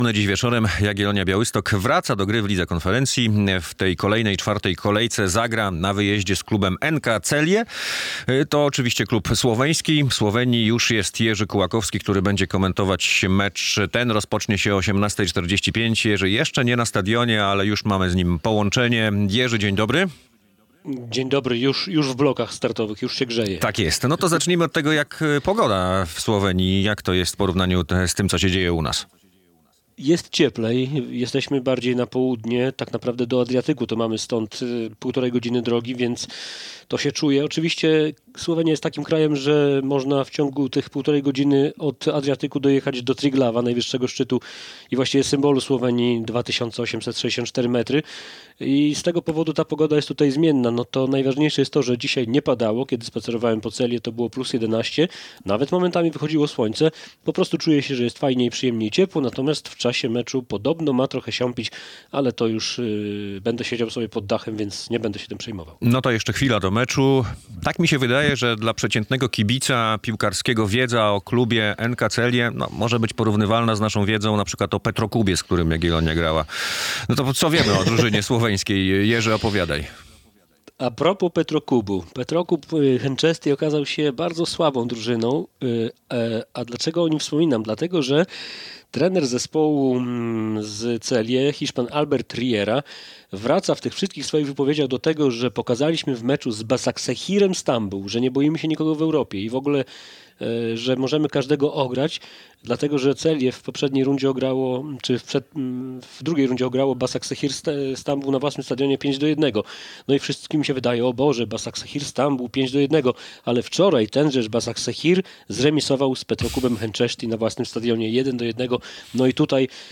przedmeczowa relacja